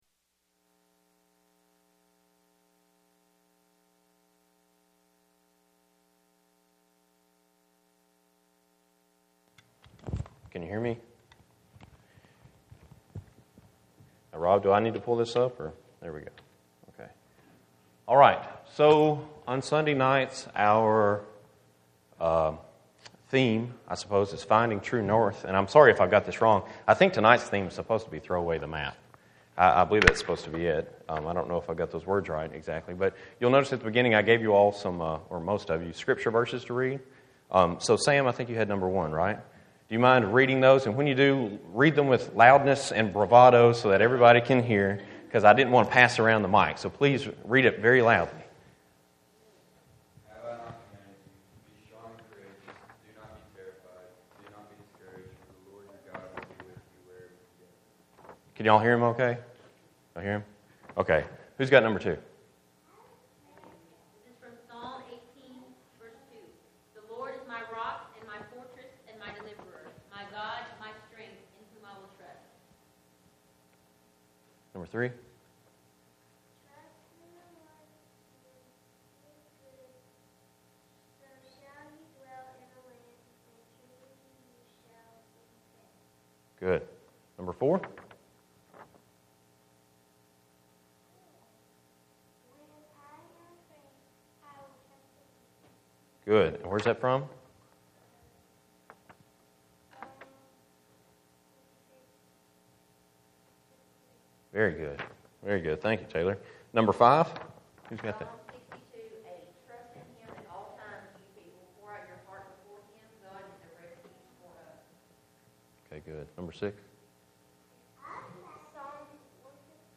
Proverbs 3:5-8 Posted in All Sermons